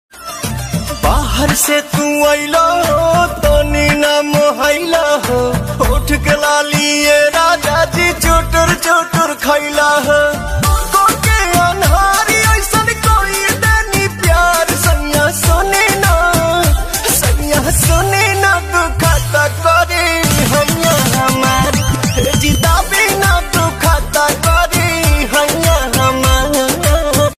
bhojpuri ringtone